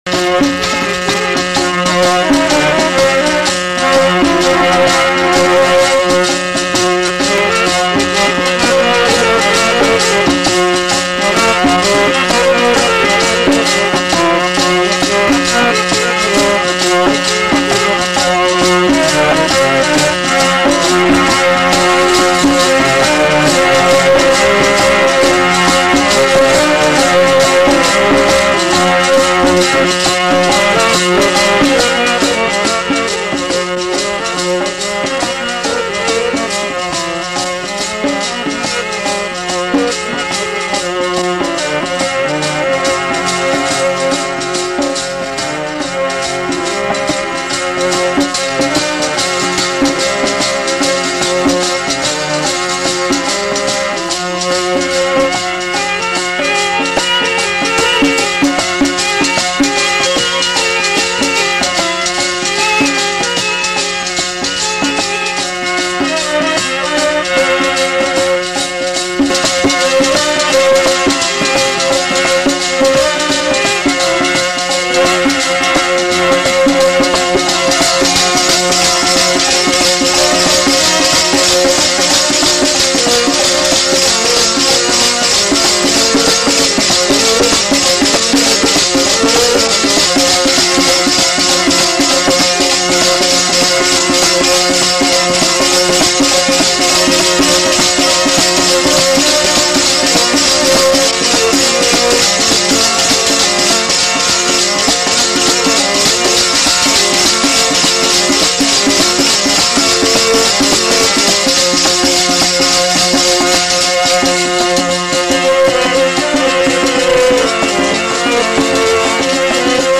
Kirtan with katha
Dropadi Da Parsang Genre: Gurmat Vichar